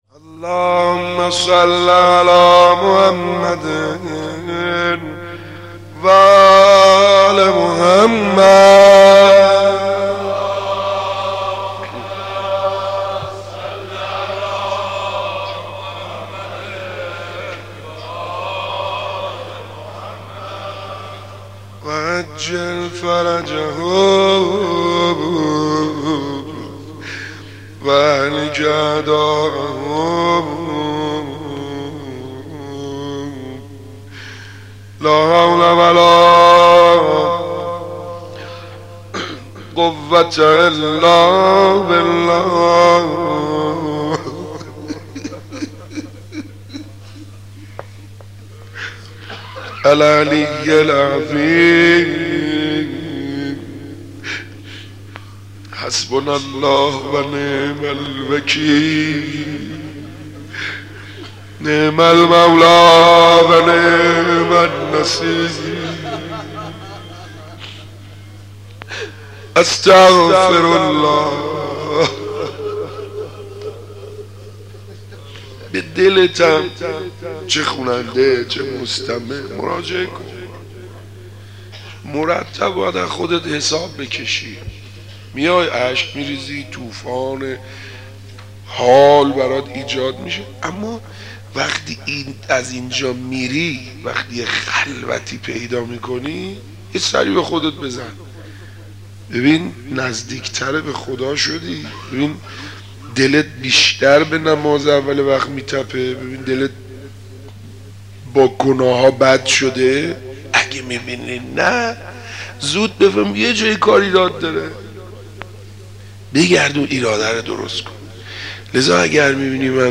سعید حدادیان مداح
مناسبت : شب هفتم محرم
مداح : سعید حدادیان